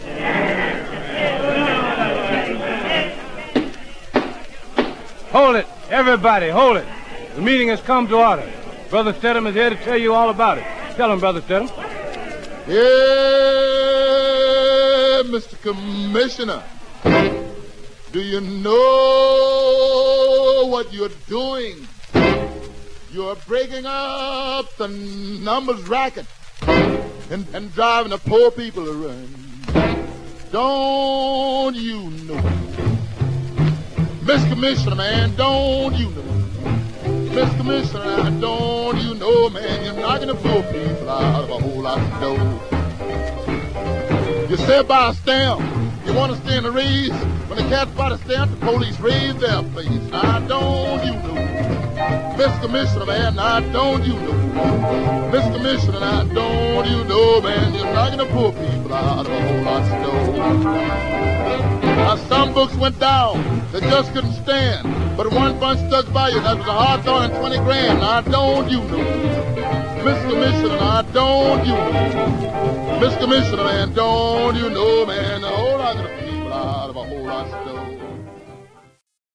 Some surface noise/wear
Mono
Blues